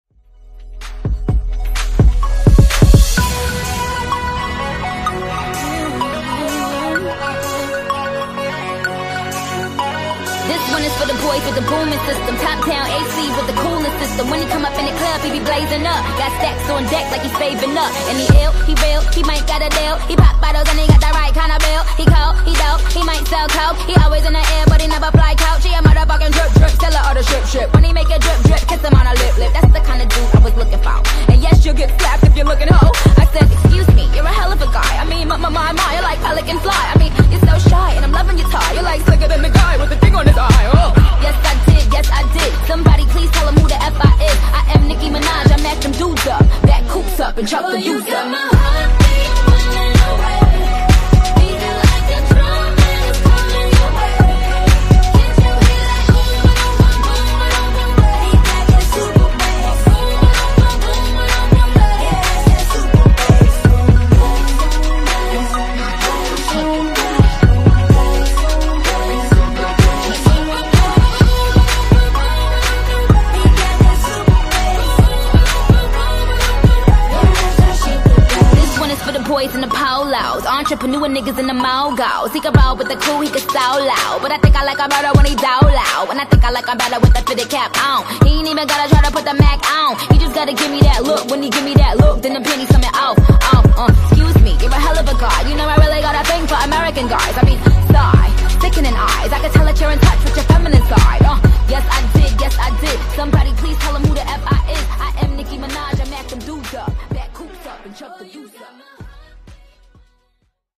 Genre: RE-DRUM
Clean BPM: 103 Time